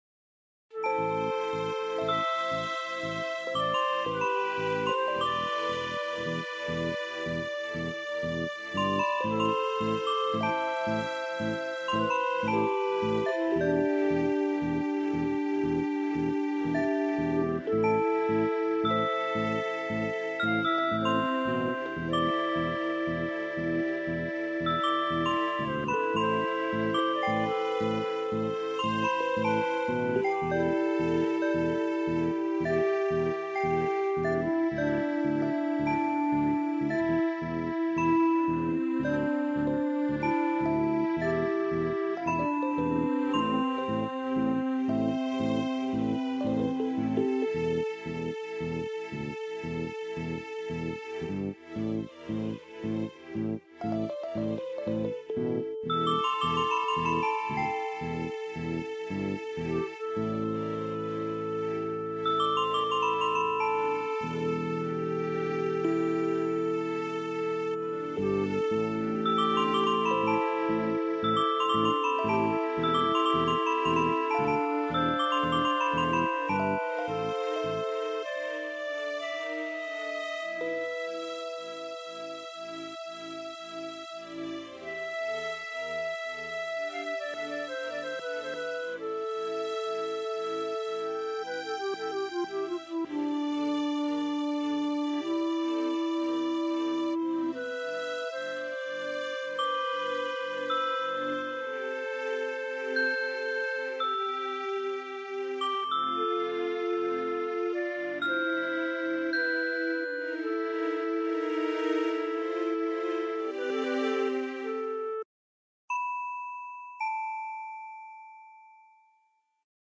Bgm Music Tune